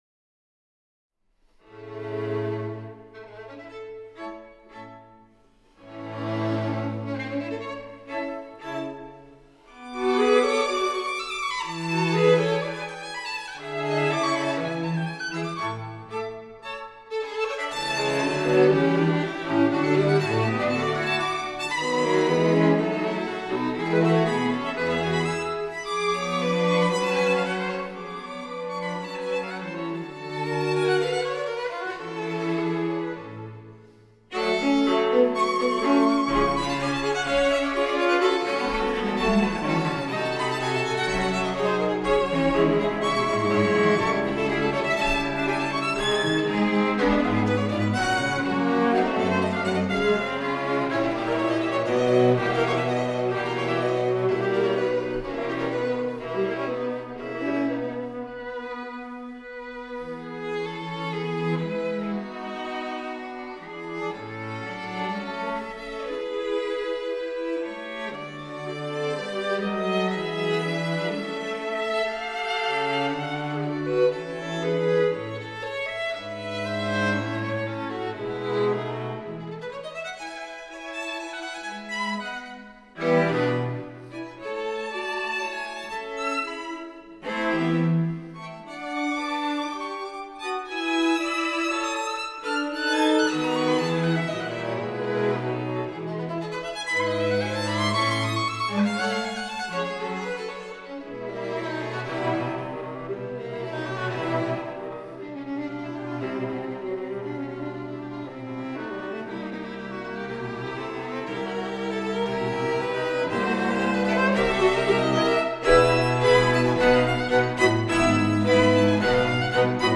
Lotus Quartet, Stuttgart
April 26. 2013 at Heidelberg-Sandhausen, Germany
struss_sq_a_major_op2.mp3